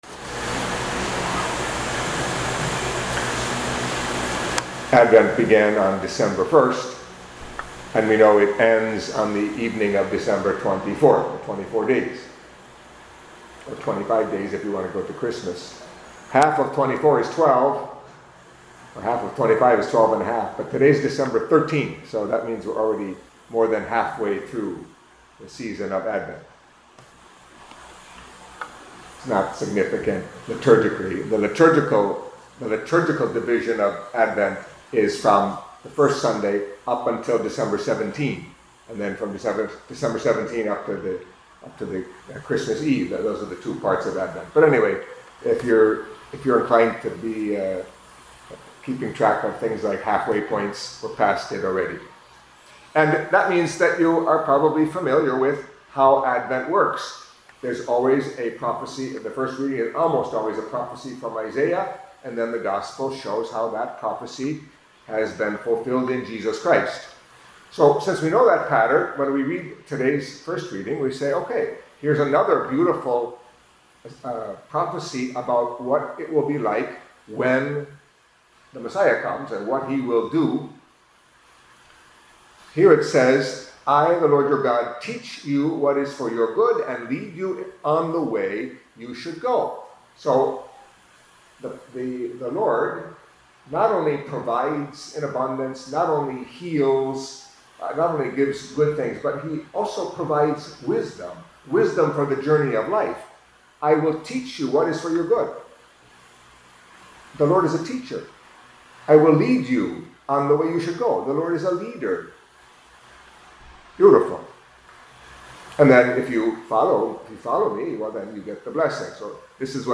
Catholic Mass homily for Friday of the Second Week of Advent